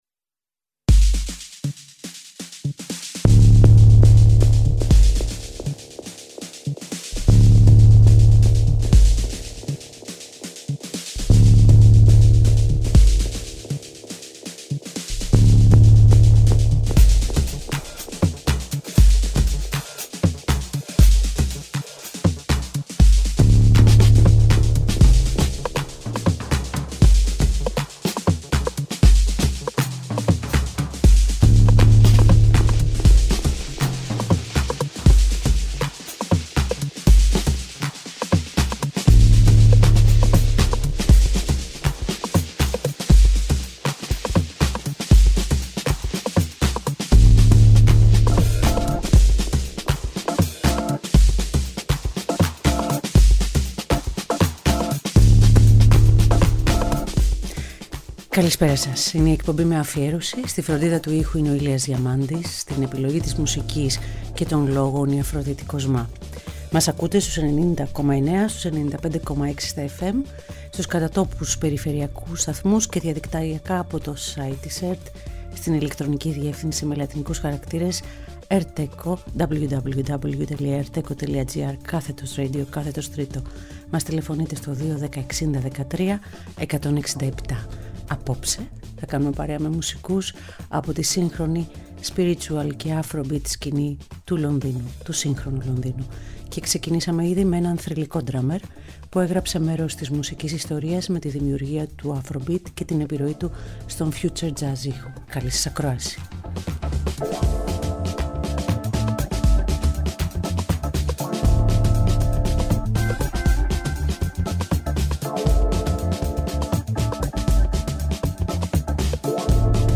Afrobeat & Spiritual Jazz London
στη σύγχρονη Afrobeat & Spiritual Jazz σκηνή του Λονδίνου